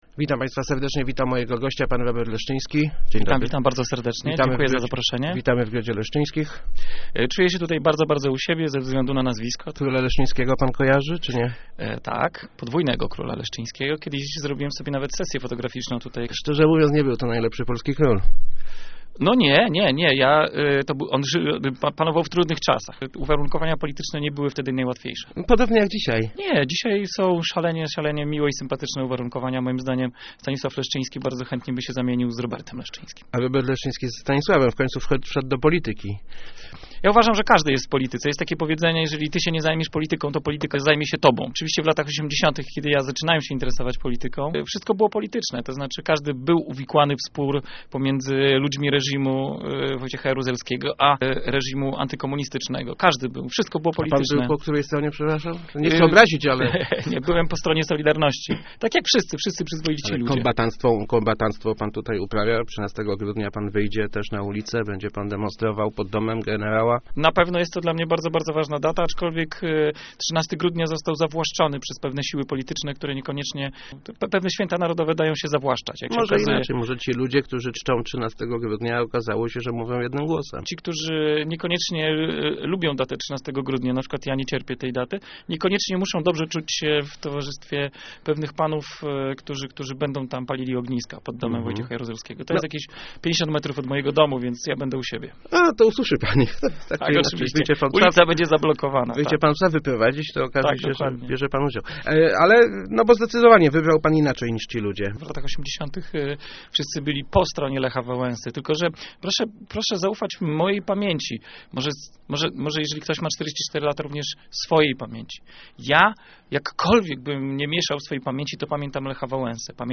Chciałbym żyć w kraju, w którym więcej wolno niż nie wolno - mówił w Rozmowach Elki Robert Leszczyński. Polityk Ruchu Palikota przyjechał do Leszna promować swoją partię.